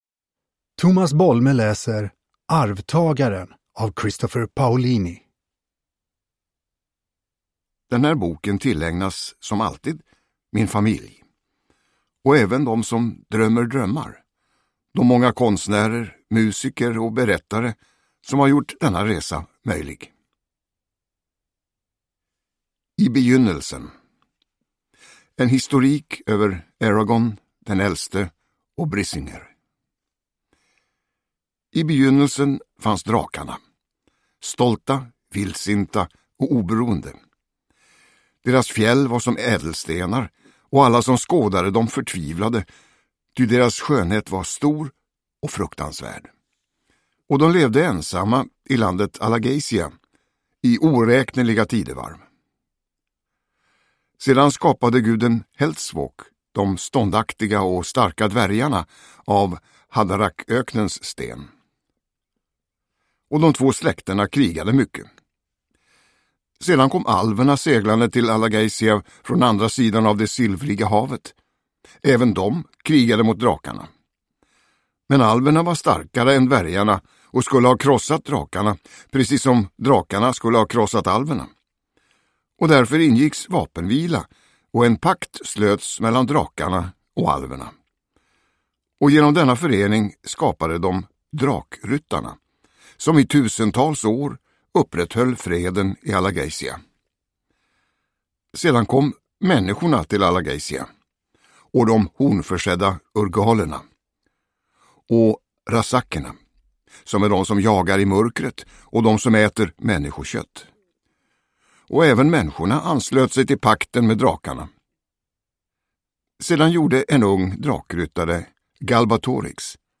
Arvtagaren – Ljudbok – Laddas ner
Uppläsare: Tomas Bolme